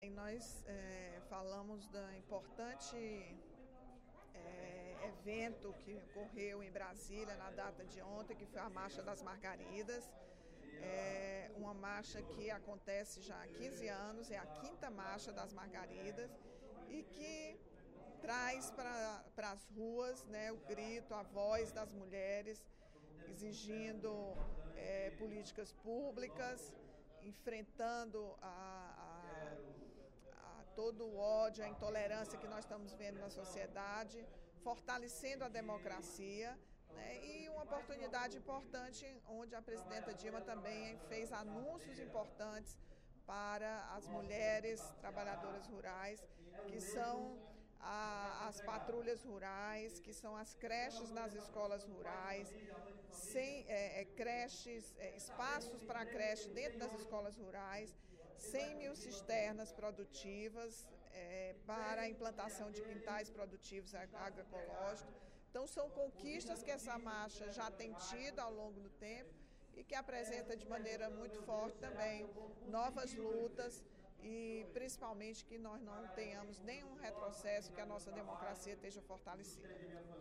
A deputada Rachel Marques (PT) destacou, no primeiro expediente da sessão plenária desta quinta-feira (13/08), a manifestação das trabalhadoras rurais conhecida como Marcha das Margaridas, realizada ontem (12).
Em aparte, os deputados Elmano Freitas (PT), Carlos Felipe (PCdoB) e Augusta Brito (PCdoB) parabenizaram a abordagem do assunto e a presença da parlamentar no movimento em Brasília.